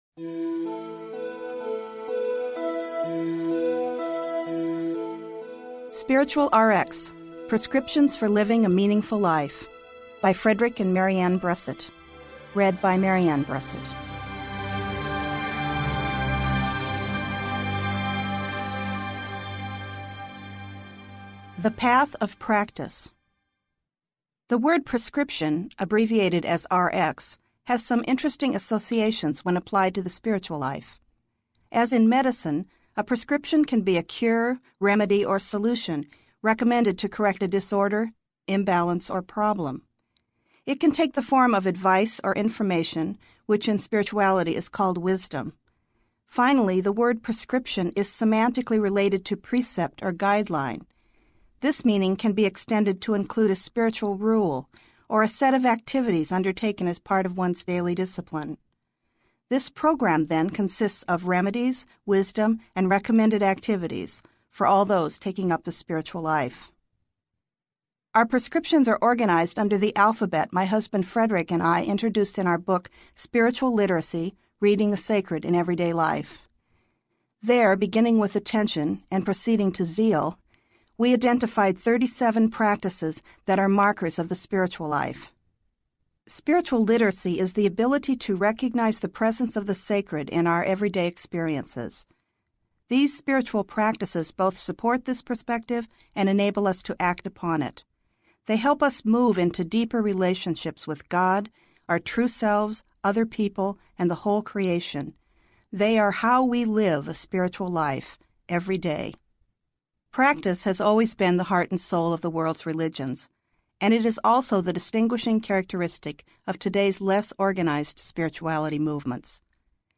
Spiritual Rx Audiobook
Narrator
3.0 Hrs. – Abridged